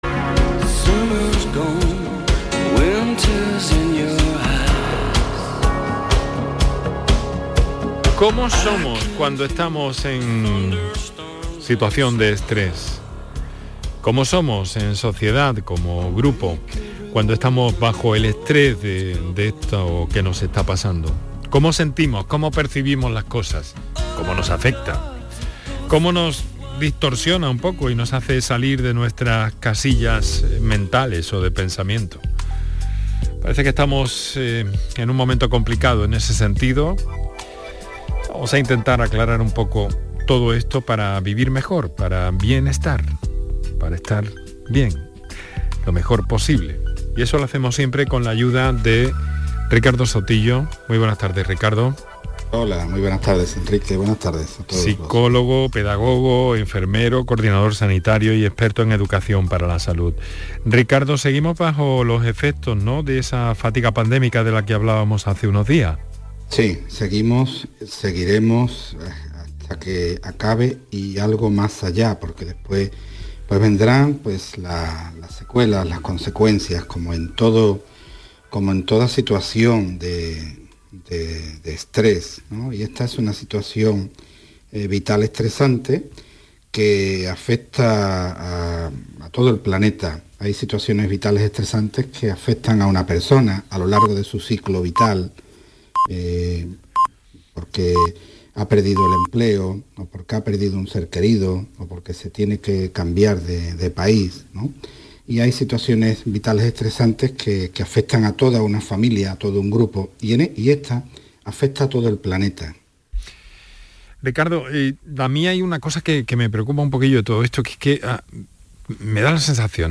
Algunos audios de intervenciones en los medios: